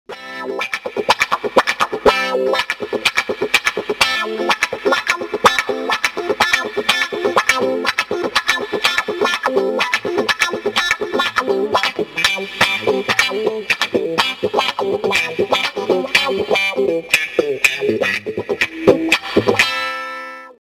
鋭いレスポンス、力強くスムーズ！まさに極上のワウ！
fulltone_clyde_wah_standard_s.mp3